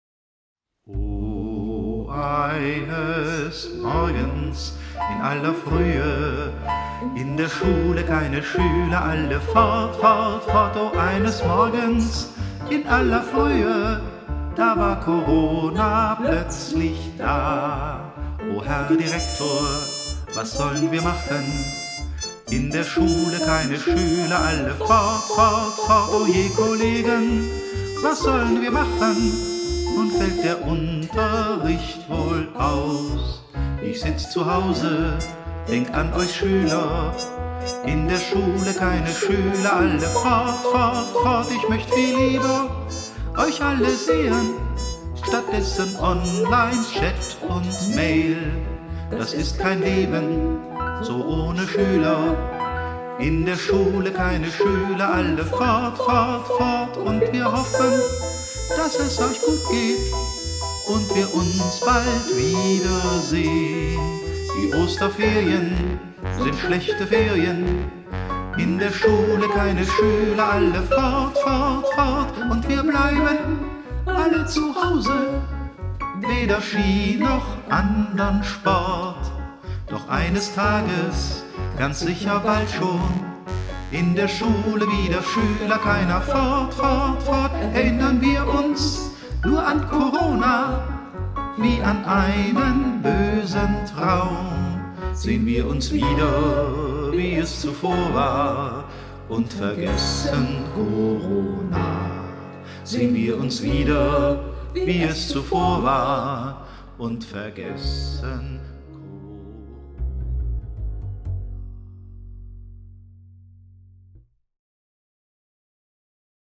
Hallo, ein kleines ganz aktuelles Projekt, die Stimmen (mit dem Handy aufgenommen) habe ich per Social Media aus NRW erhalten. Bassposaune und gemutete Trompete sind von Native Instruments (Session Horns Pro), das Piano ist von Modartt (Pianoteq 6, Steinway D Prelude). Die wenigen Effekte (Compressor, Reverb, EQ) stammen aus Logic Pro X. Eine Idee eines Lehrerkollegen meiner Schwester.